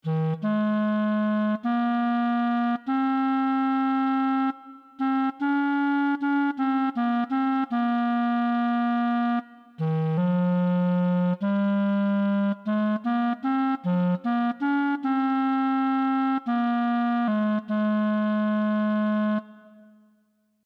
As a first approach, we have assumed that a MIDI-controlled digital clarinet synthesiser based on physical models is a sufficiently good instrument model.